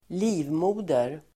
Ladda ner uttalet
livmoder.mp3